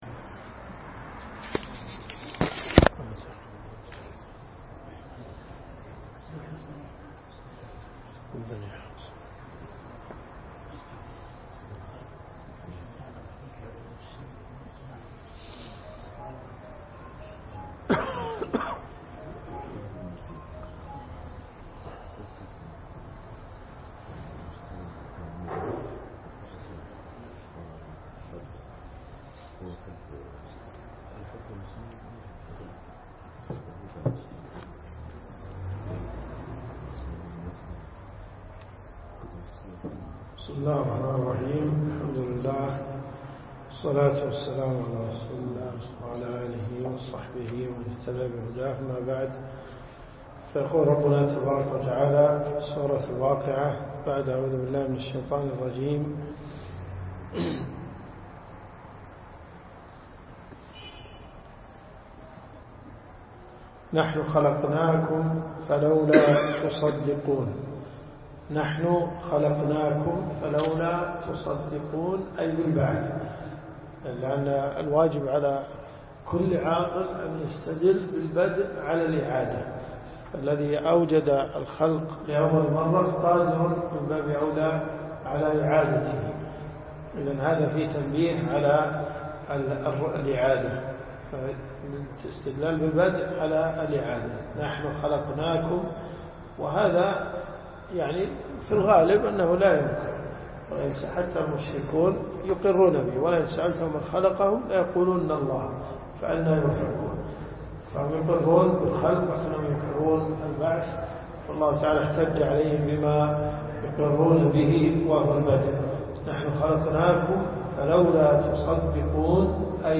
دروس صوتيه ومرئية تقام في جامع الحمدان بالرياض